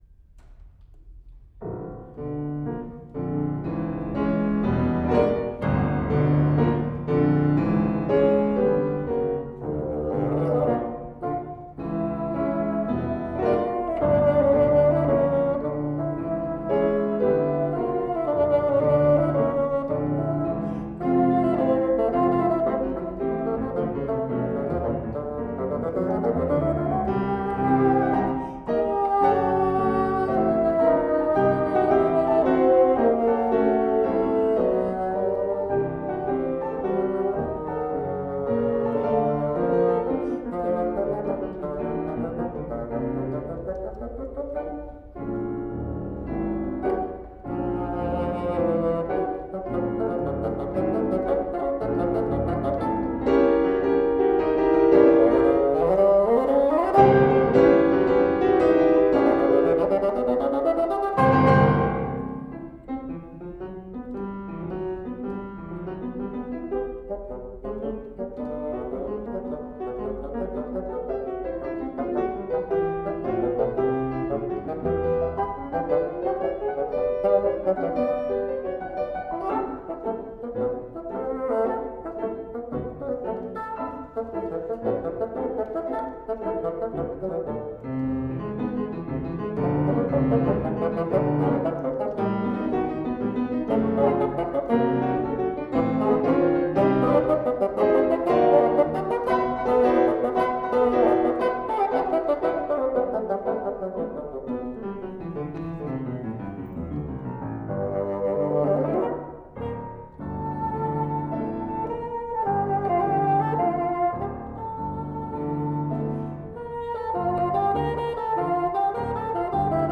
DLA koncert live concert